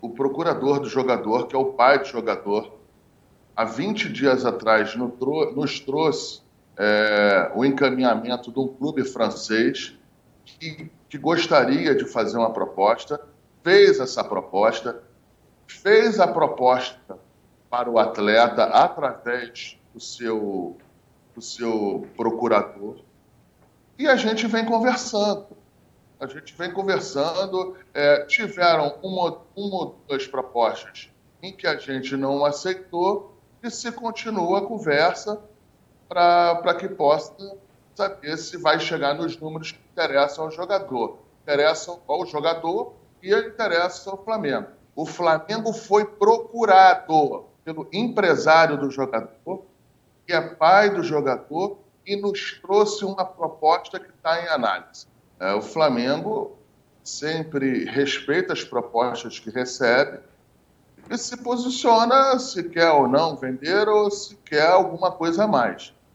Em entrevista ao programa Os Donos da Bola, da TV Bandeirantes, o vice de futebol, Marcos Braz, analisou a negociação:
Ouça abaixo a declaração do vice-presidente de futebol do Clube: